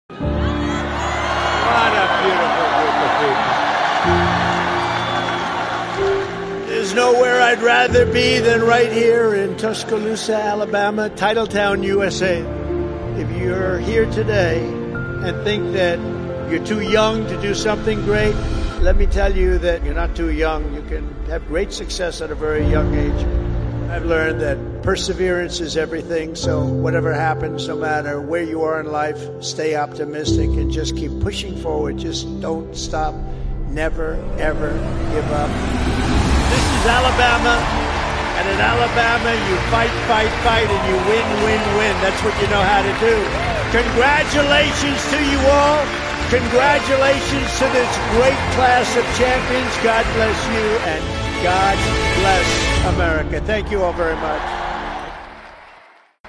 President Donald J. Trumps Commencement Address at the University of Alabama
president-donald-j-trumps-commencement-address-at-the-university-of-alabama.mp3